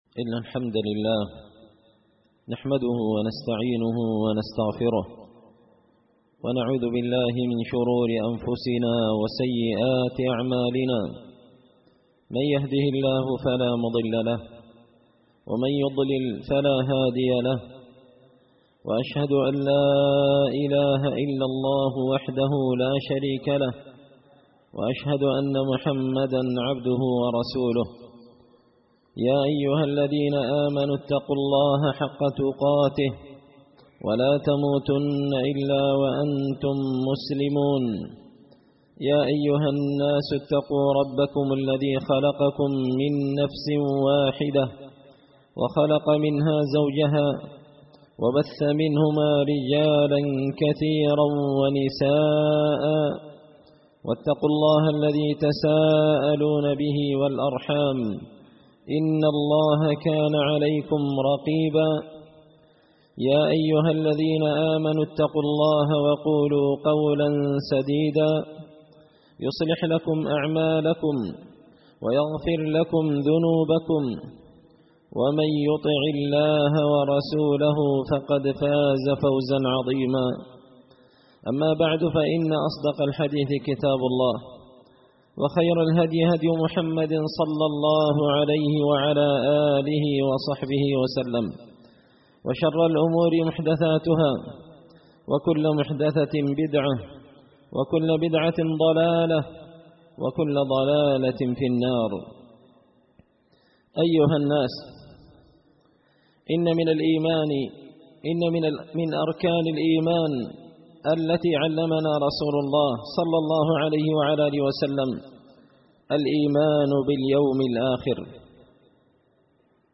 خطبة جمعة بعنوان – أحوال بعض العصاة يوم القيامة
دار الحديث بمسجد الفرقان ـ قشن ـ المهرة ـ اليمن